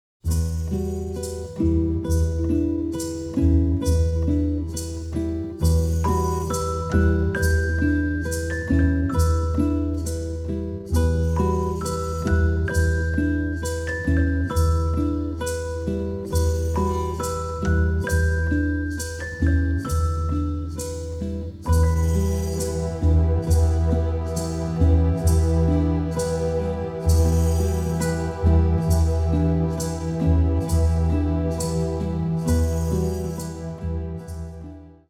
memorable, energetic and varied score